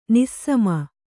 ♪ nissama